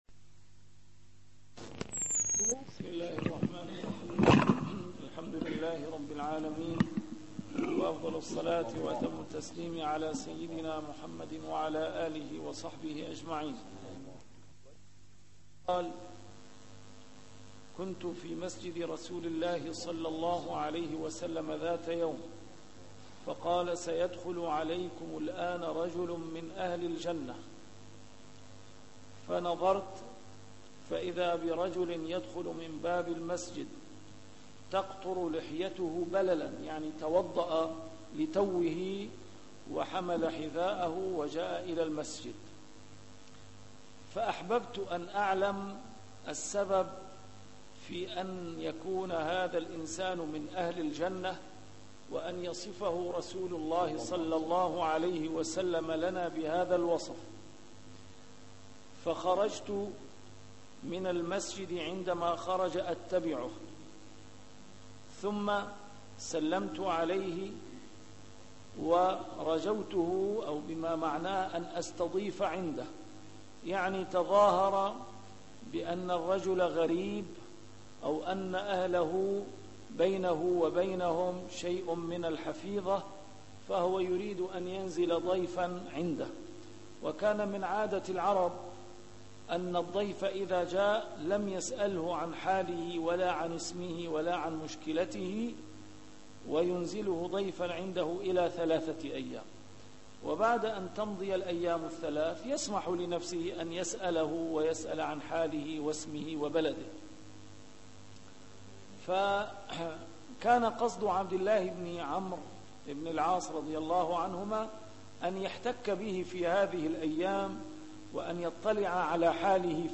A MARTYR SCHOLAR: IMAM MUHAMMAD SAEED RAMADAN AL-BOUTI - الدروس العلمية - شرح الأحاديث الأربعين النووية - تتمة شرح الحديث الخامس والثلاثون: حديث أبي هريرة (لا تحاسدوا ولا تناجشوا …) 114